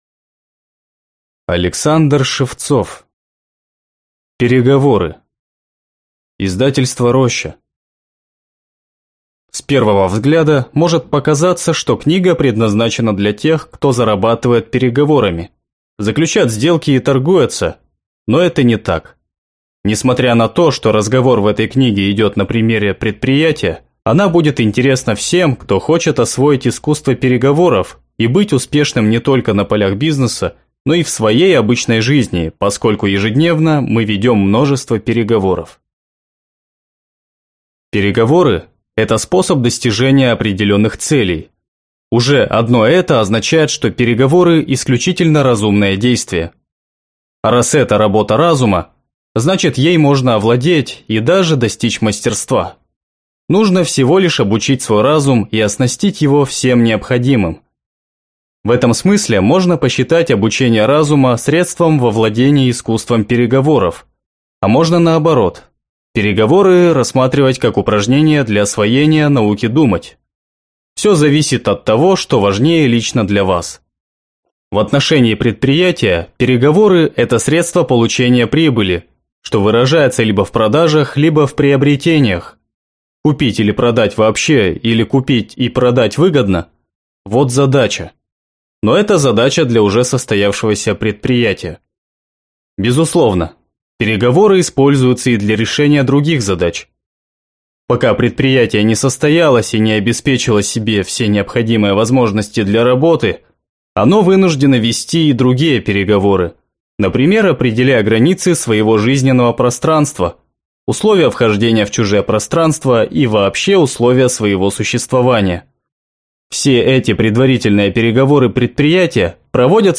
Аудиокнига Переговоры | Библиотека аудиокниг